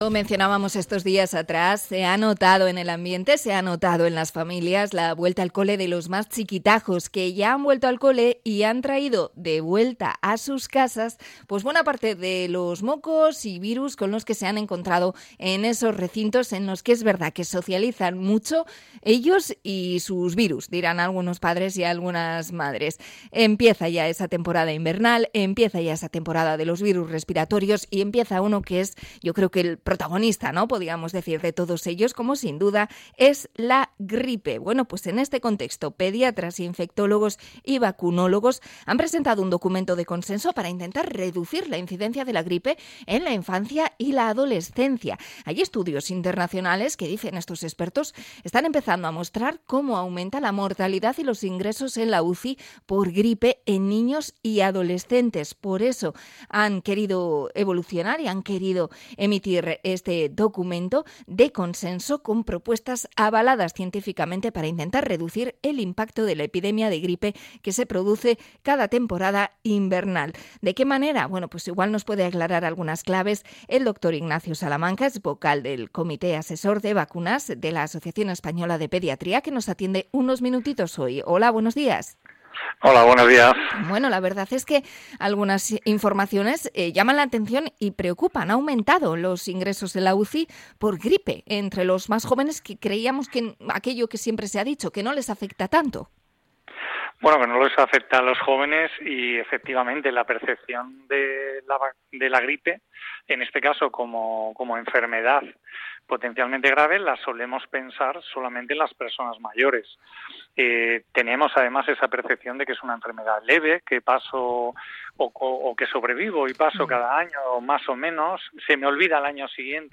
Entrevista a pediatra por la vacunación de la gripe